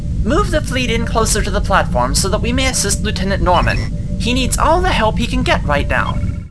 Human Male, Age 61